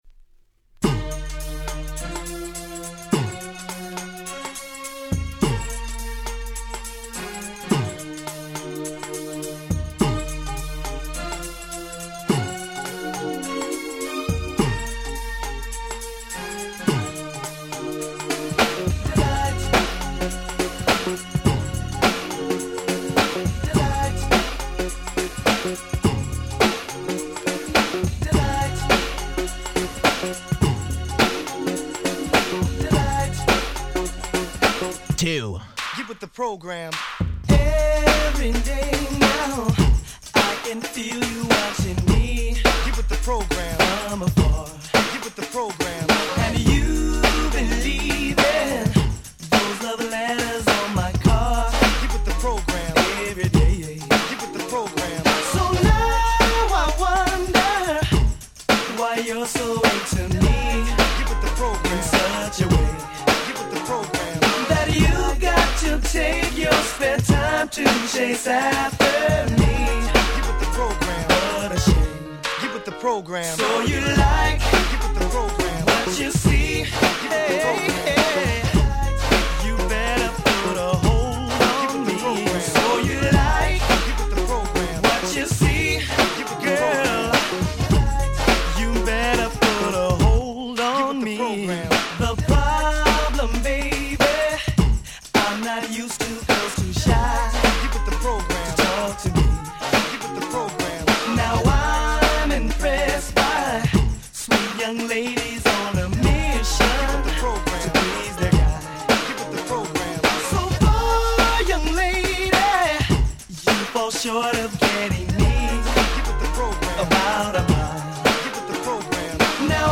90' Nice New Jack Swing !!
バッキバキに跳ねた鉄板New Jack Swing !!